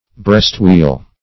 Meaning of breastwheel. breastwheel synonyms, pronunciation, spelling and more from Free Dictionary.
Breastwheel \Breast"wheel`\ (br[e^]st" hw[=e]l`), n.